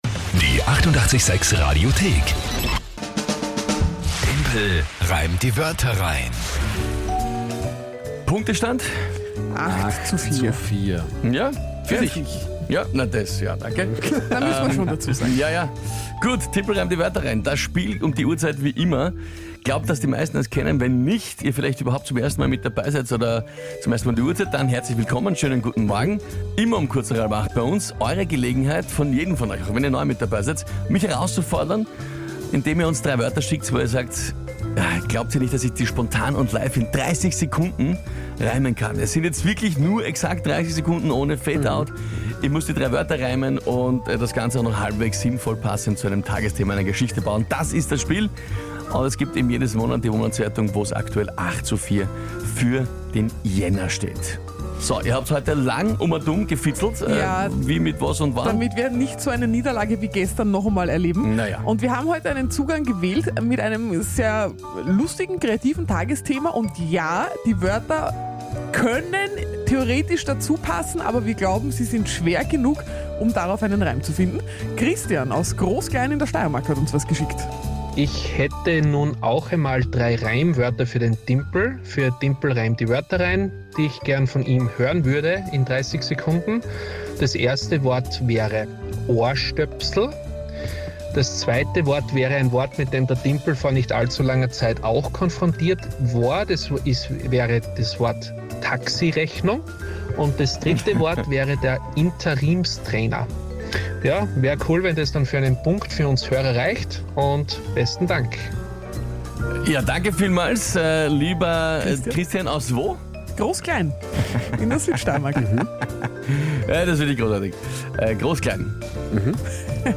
Komödie Österreich Radio886
Der Timpel muss es schaffen, innerhalb von 30 Sekunden, drei von einem Hörer vorgegebenen Wörter zu einem aktuellen Tagesthema in einen Reim einzubauen.